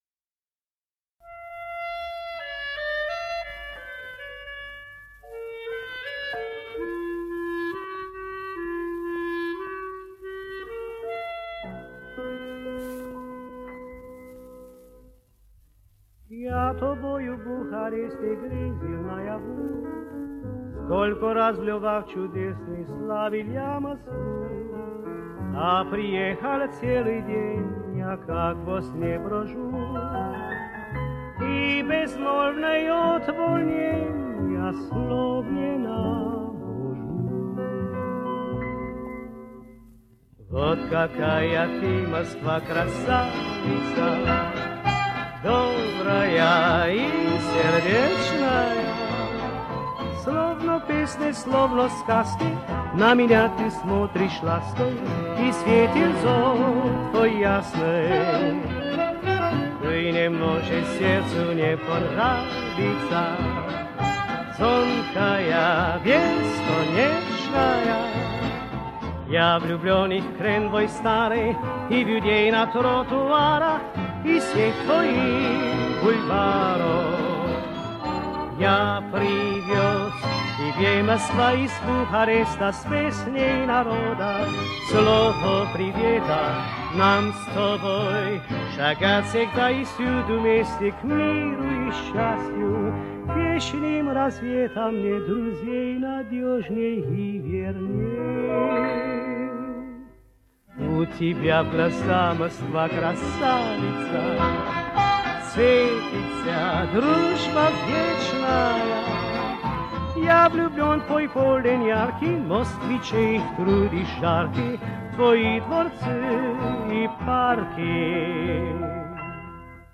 Румынская же песня лирическая, задушевная...
оркестр